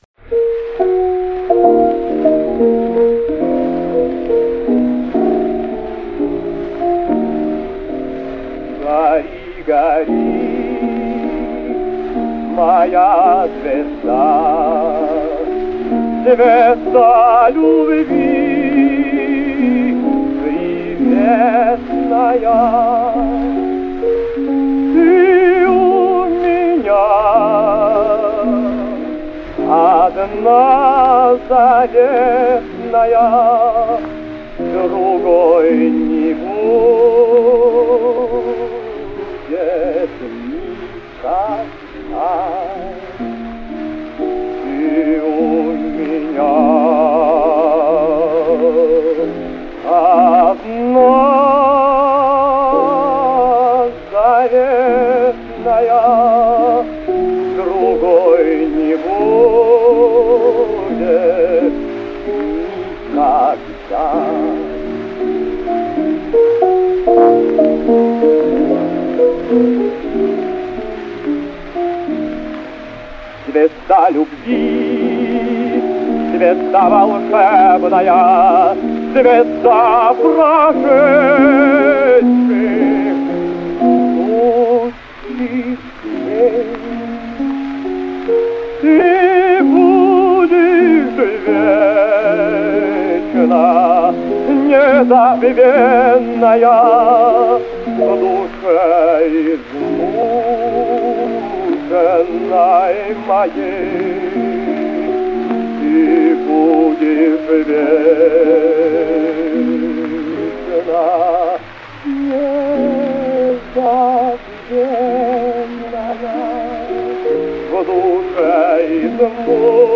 А после того, как в 1915 году свет увидела пластинка с записью Сабинина, романс запела без преувеличения вся страна.
Поет Владимир Сабинин.....